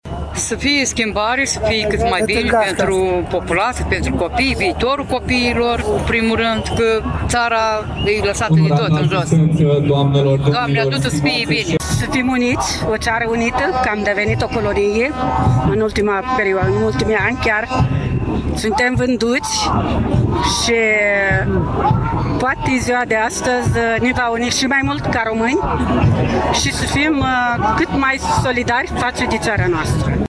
Mii de oameni au asistat, dimineață, la ceremoniile organizate în Piața Unirii. Pe tot parcursul acestora, mulțimea a scandat lozinci antiguvernamentale şi antisistem și a huiduit.
24-ian-rdj-20-Vox-Ziua-Unirii.mp3